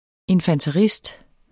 Udtale [ enfantəˈʁisd ]